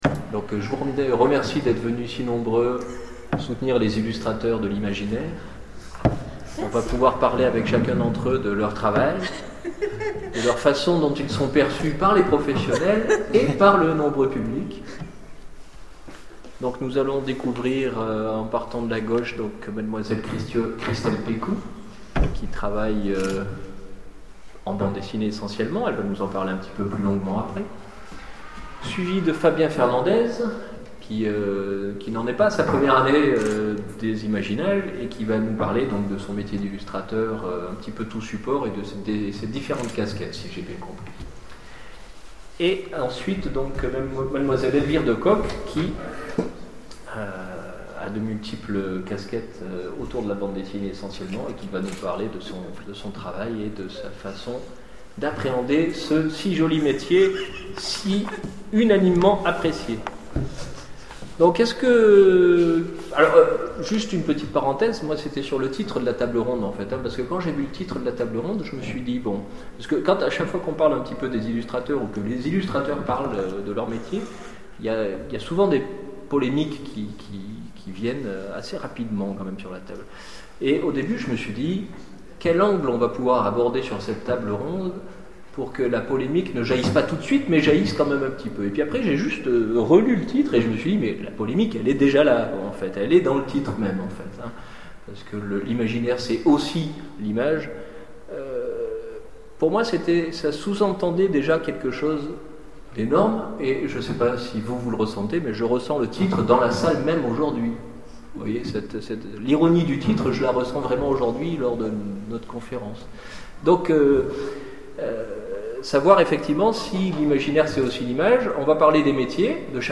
Imaginales 2012 : Conférence L'imaginaire c'est aussi l'image !